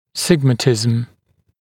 [‘sɪgmətɪzəm][‘сигмэтизэм]сигматизм, шепелявость (нарушение произношения шипящих и свистящих звуков)